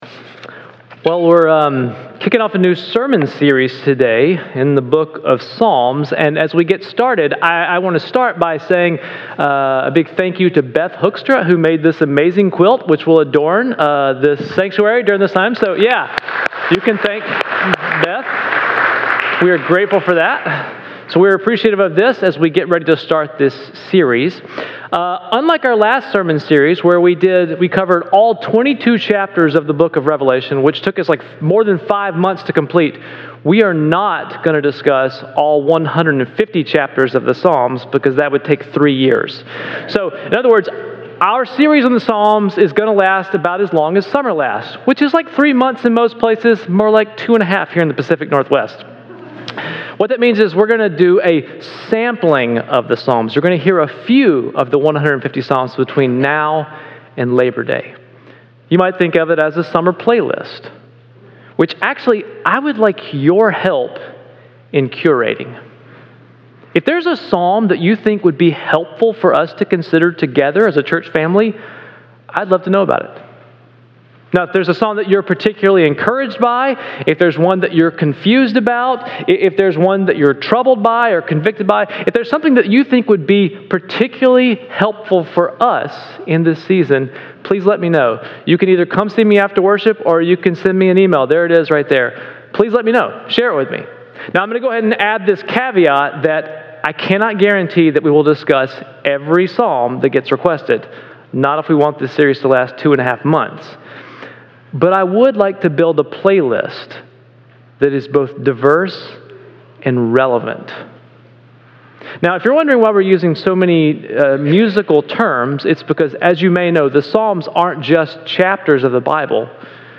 Audio Scriptures